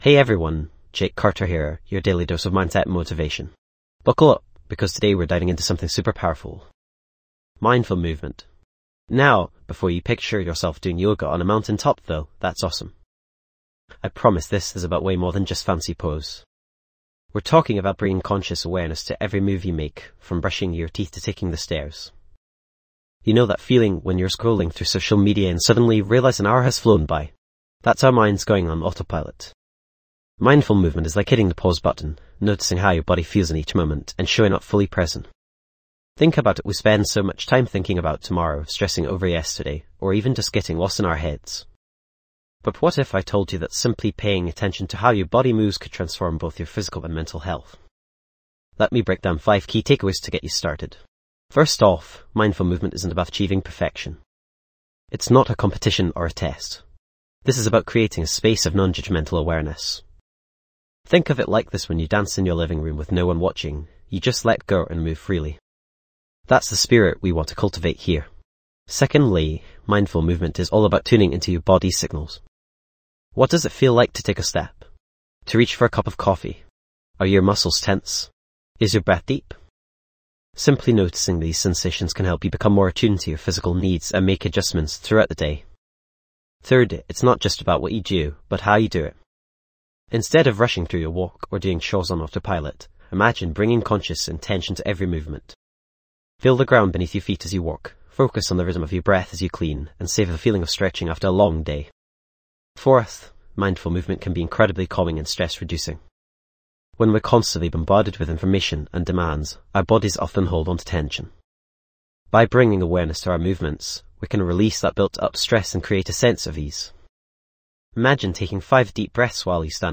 Episode Tags:. Mindful movement, Physical health, Mental health, Focus, Well-being, Fitness, Mindfulness, Daily routine, Quick tips, Guided exercise, Science of mindfulness
This podcast is created with the help of advanced AI to deliver thoughtful affirmations and positive messages just for you.